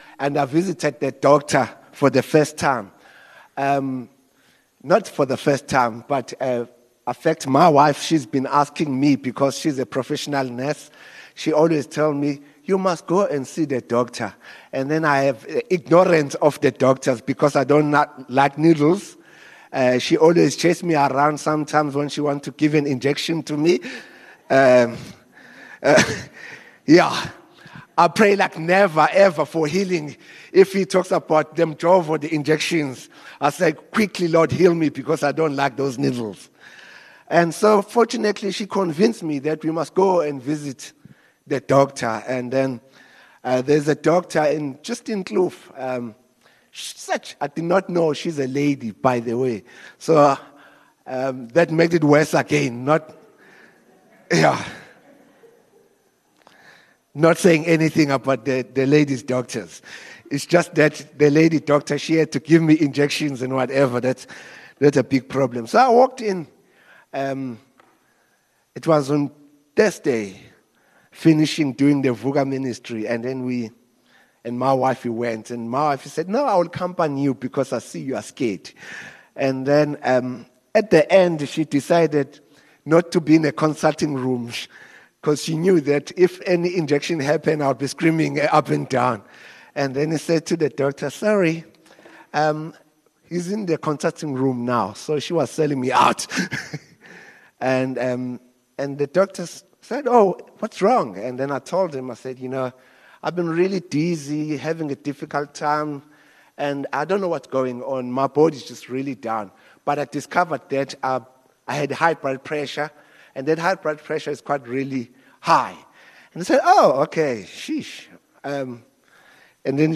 View Promo Continue JacPod Install Upper Highway Vineyard Sunday messages 11 Aug Let your light shine